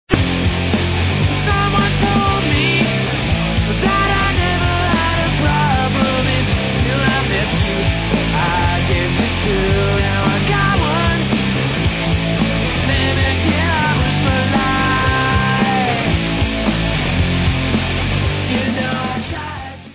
4-chord punk pop